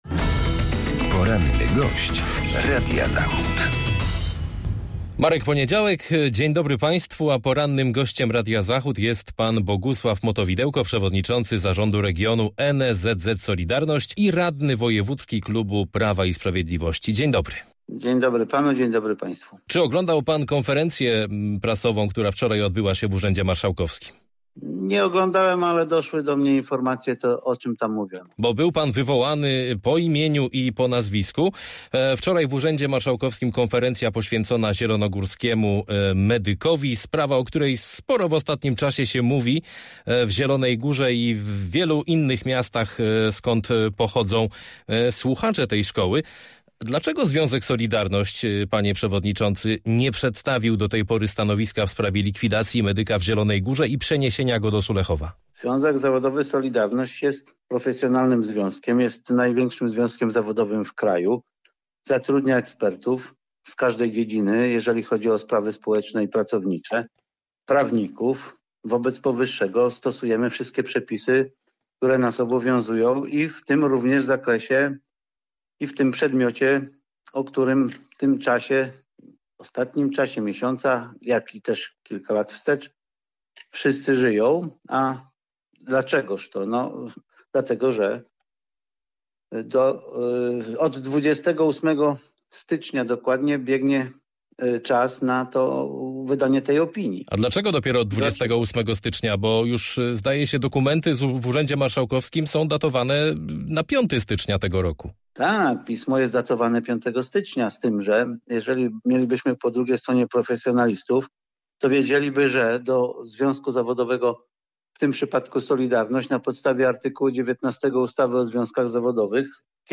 Z przewodniczącym zielonogórskiej „Solidarności”, radnym klubu PiS w sejmiku rozmawia